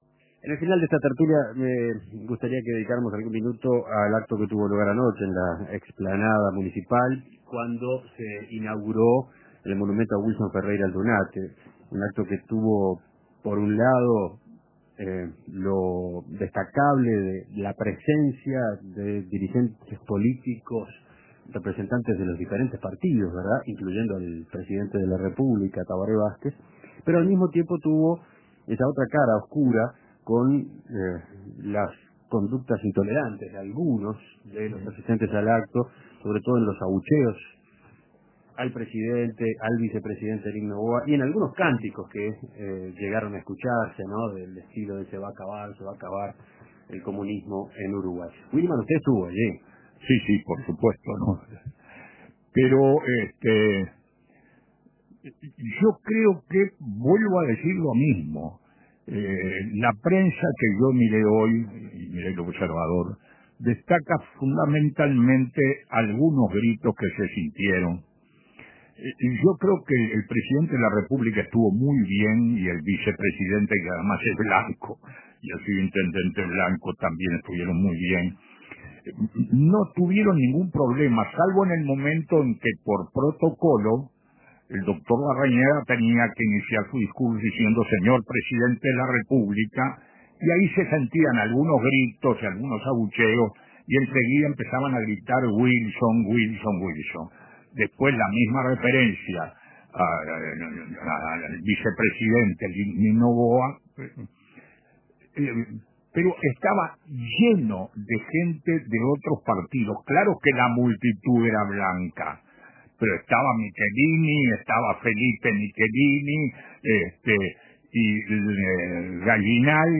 Con algunos abucheos a figuras del gobierno, dirigentes de todos los partidos inauguraron el monumento a Wilson